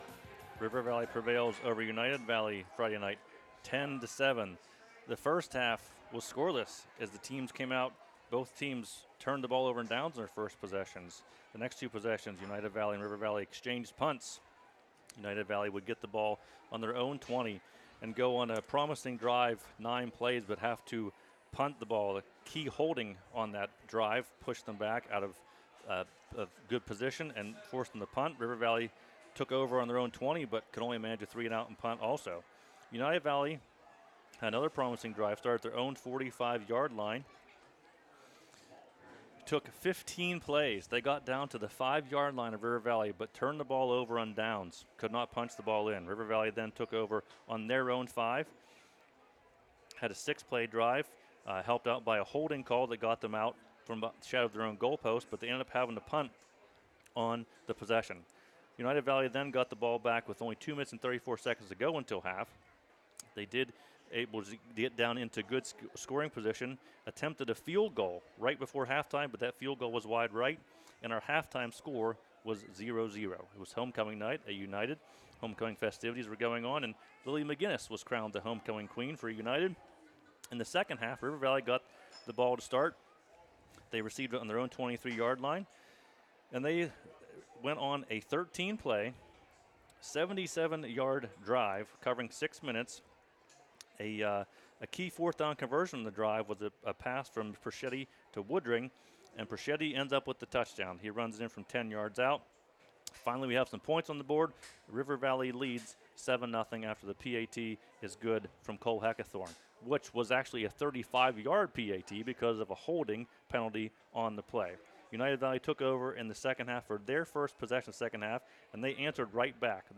recap-united-valley-v-river-valley-9-26.mp3